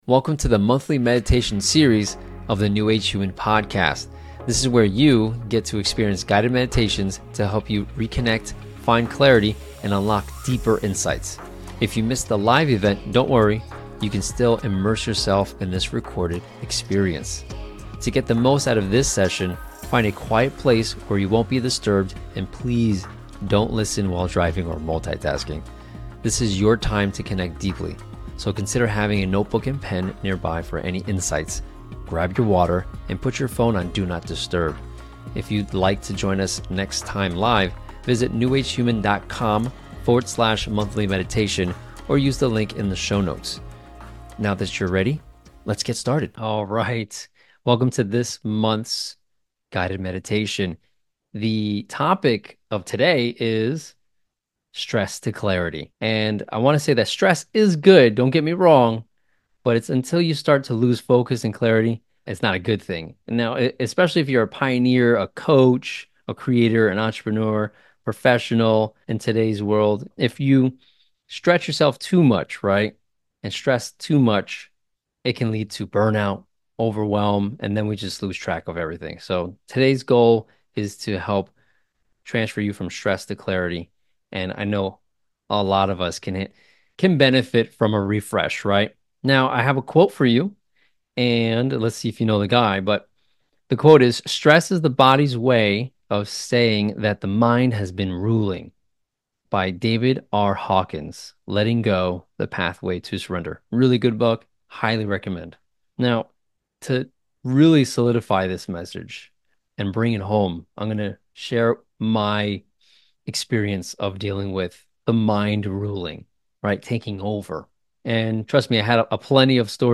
Welcome to the New Age Human monthly meditation series, where we guide you through themed meditations designed to help you reconnect with yourself, find clarity, and unlock deeper insights. This episode offers a recorded version of our live session, allowing you to experience the meditation at your convenience.